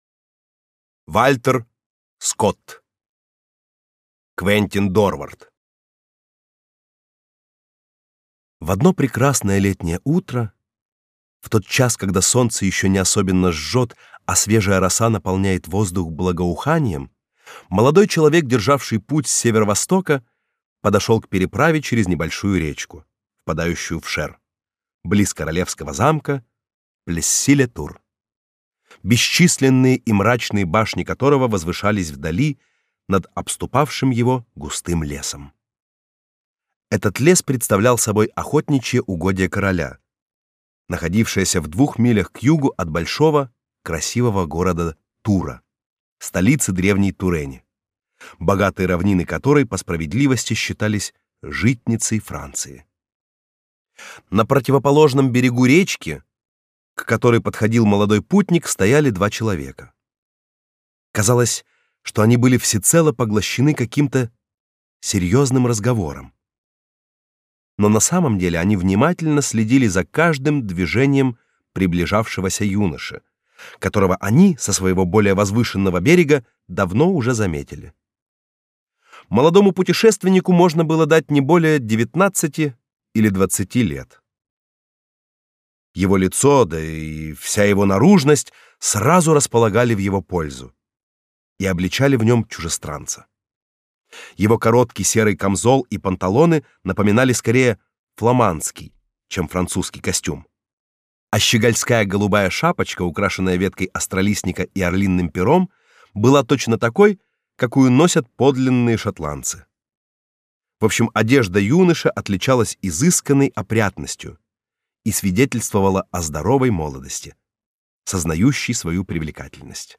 Аудиокнига Квентин Дорвард | Библиотека аудиокниг
Прослушать и бесплатно скачать фрагмент аудиокниги